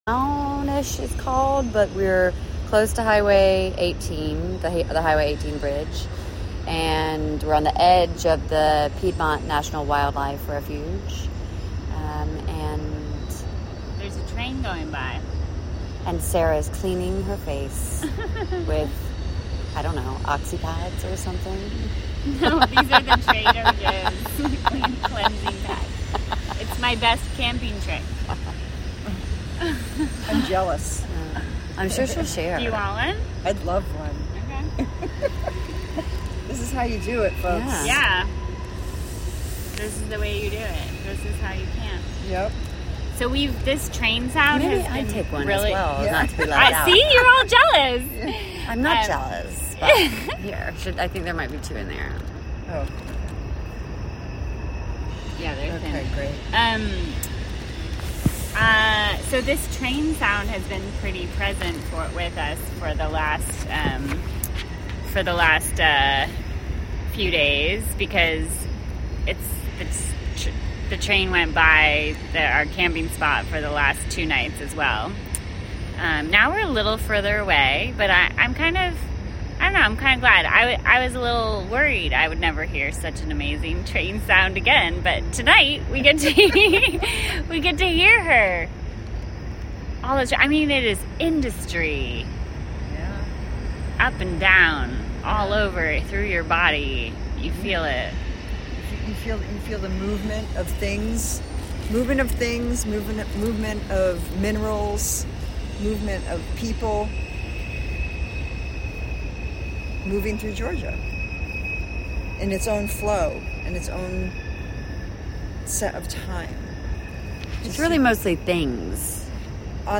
report from the edge of the Piedmont National Wildlife Refuge on the Ocmulgee River